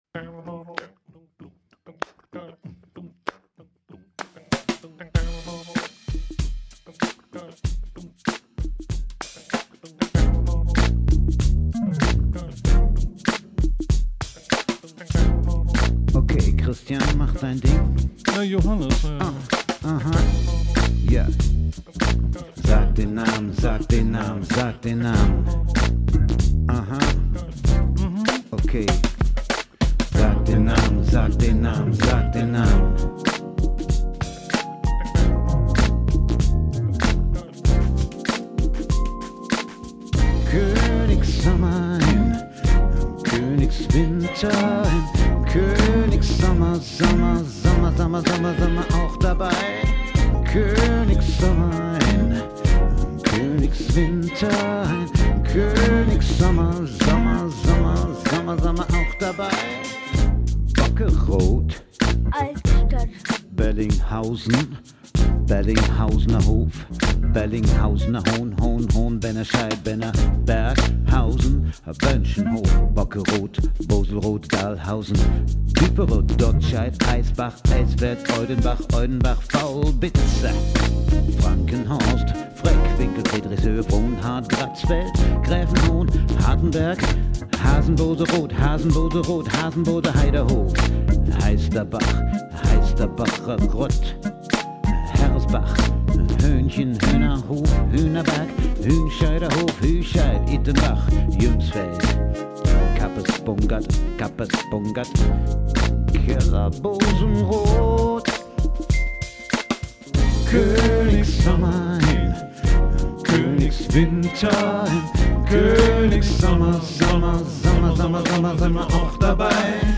Ein Lied für Königswinter:
Gesang, Keyboards, Drums & Percussion